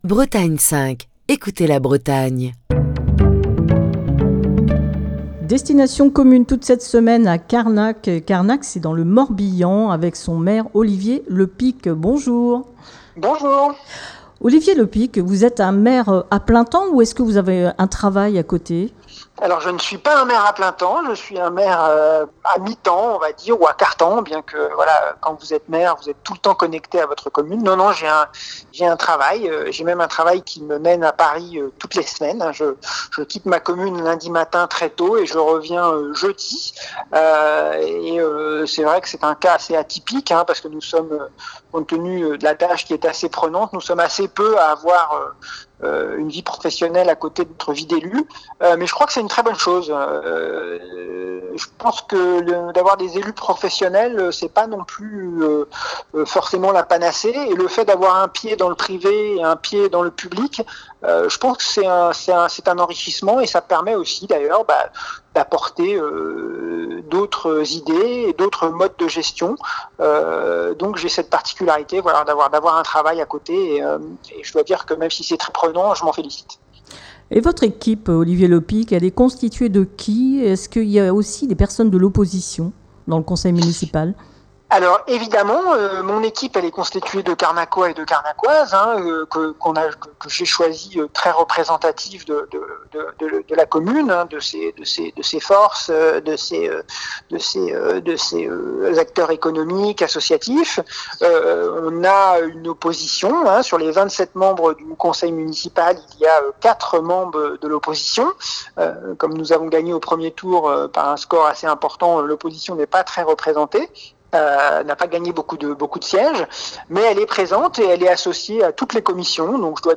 Cette semaine, Destination commune pose ses micros à Carnac, dans le Morbihan.